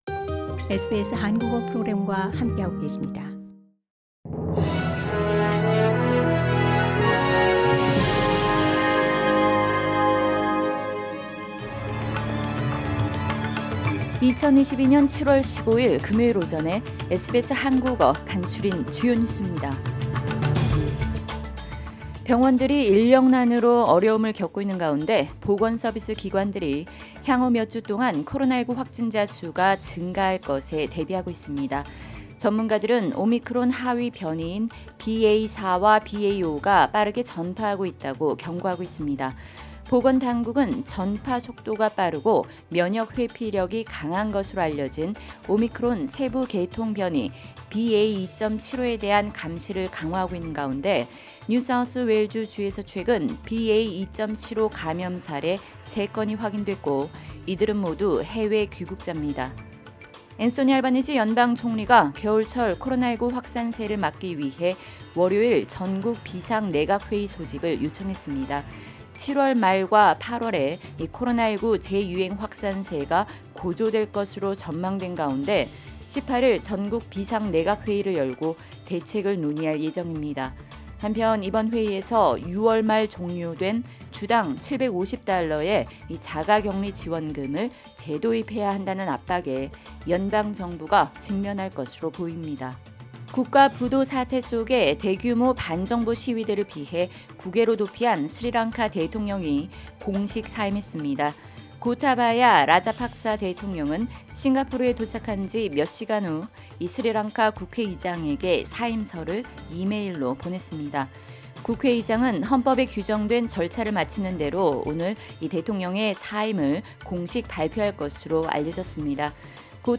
SBS 한국어 아침 뉴스: 2022년 7월 15일 금요일